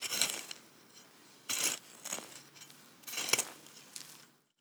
SFX_Harke_02.wav